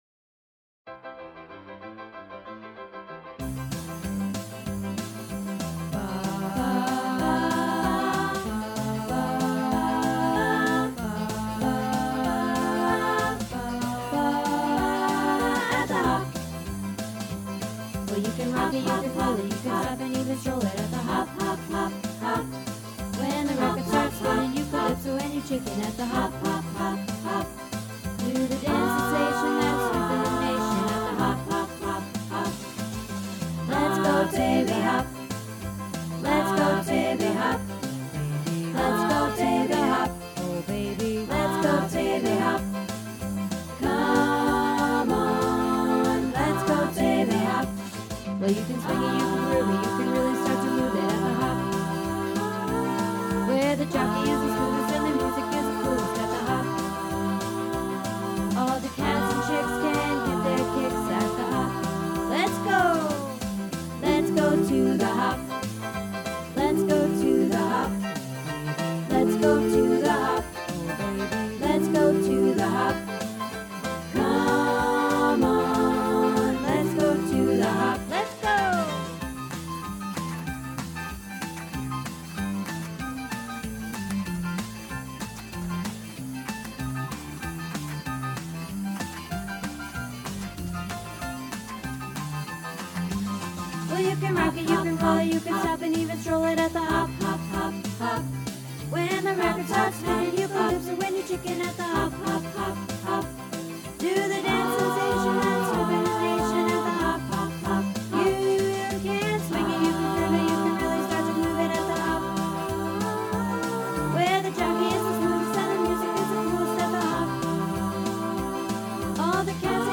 At the Hop - Performance